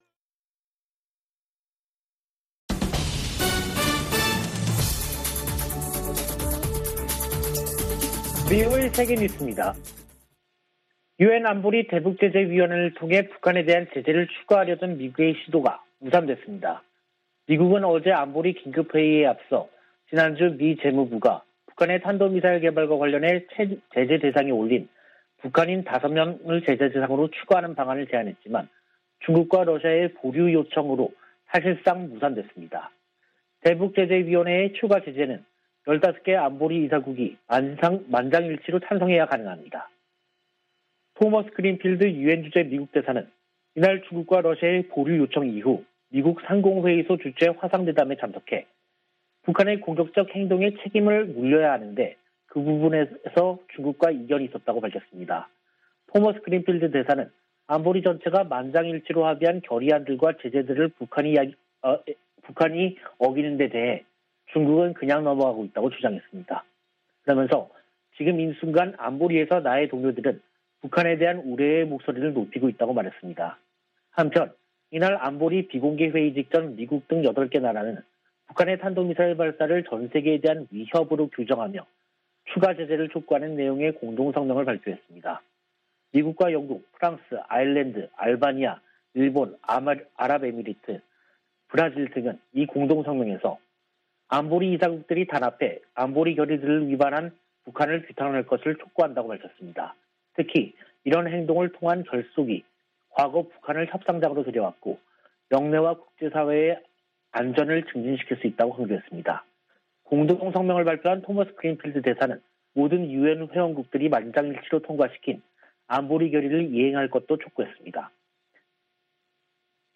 VOA 한국어 간판 뉴스 프로그램 '뉴스 투데이', 2022년 1월 21일 3부 방송입니다. 미국 등 8개국이 북한의 탄도미사일 발사를 전 세계에 대한 위협으로 규정하고 유엔에서 추가 제재를 촉구했습니다. 백악관은 북한이 무기 시험 유예를 해제할 수 있다는 뜻을 밝힌 데 대해, 대량살상무기 개발을 막을 것이라고 강조했습니다. 북한이 선대 지도자들의 생일을 앞두고 열병식을 준비하는 동향이 포착됐습니다.